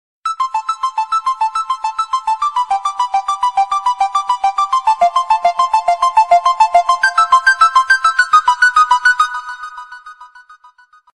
без слов
звонок